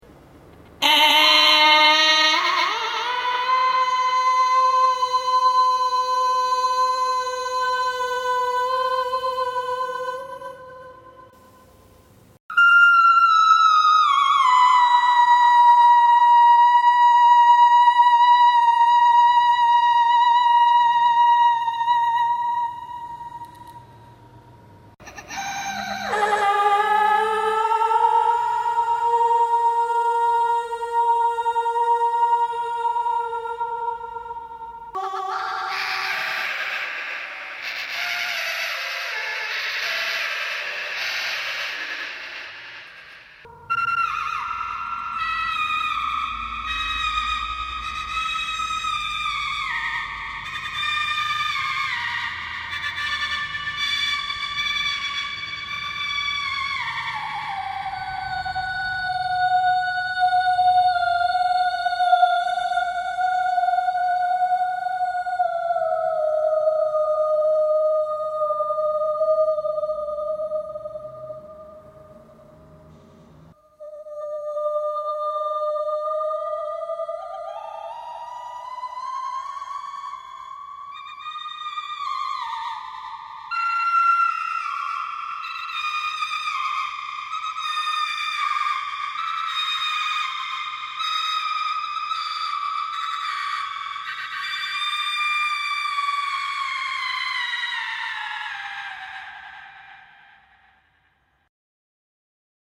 High pitch, trills and warbles…
“Sirening” Decreased tempo of Version 1 to capture moments of vocal transitioning
birds-slowed-down-website.mp3